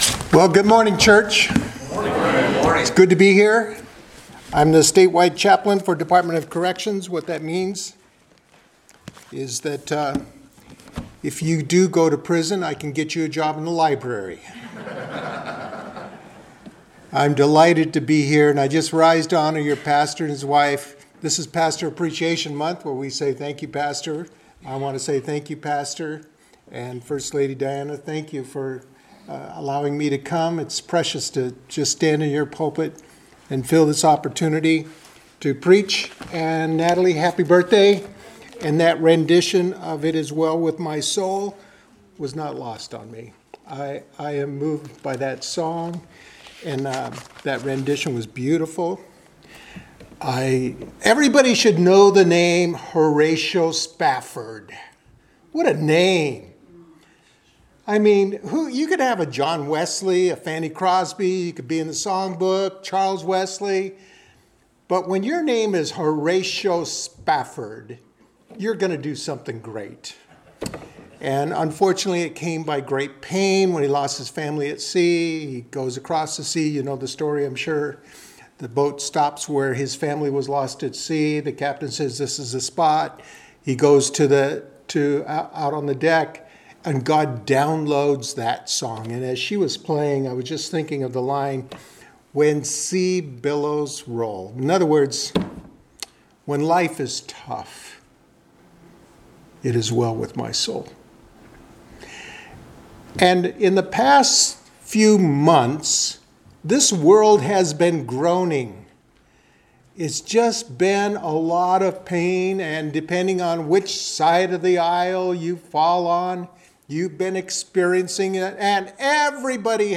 Service Type: Sunday Morning Worship Topics: Run with Good News